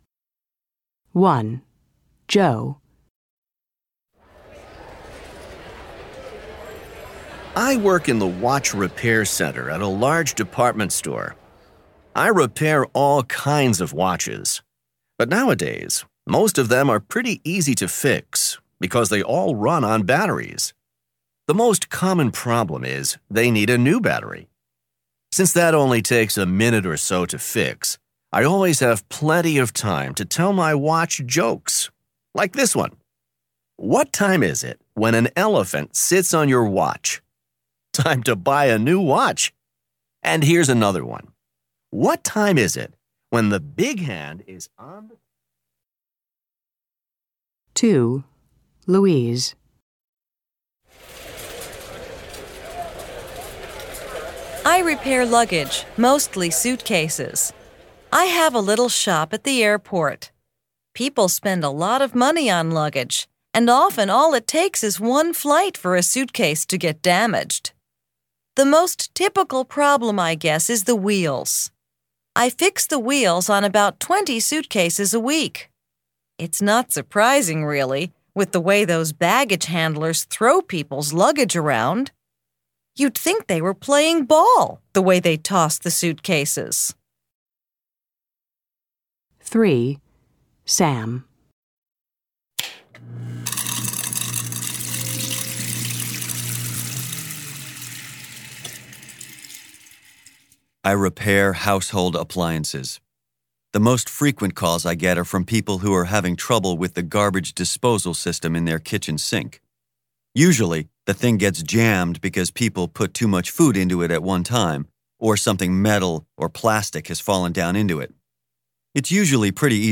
Listen to three people talk about their jobs: